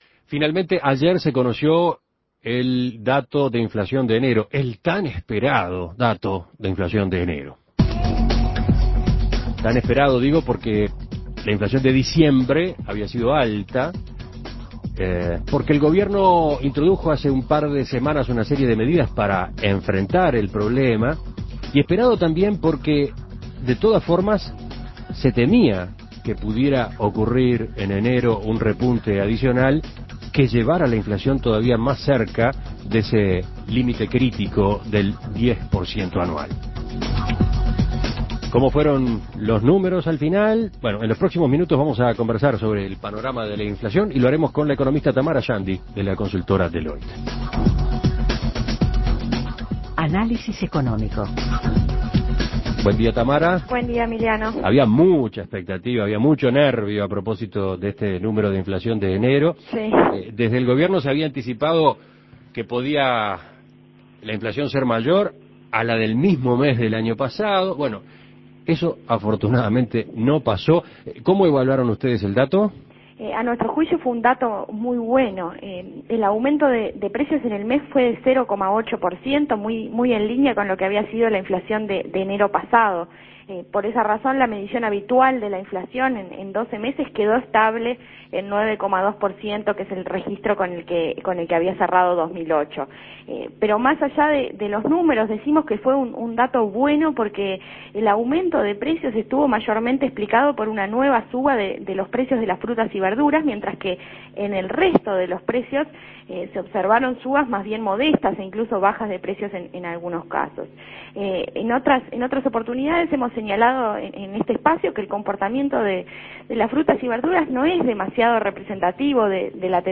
Análisis Económico ¿Cómo queda planteado el panorama de inflación tras el dato de enero?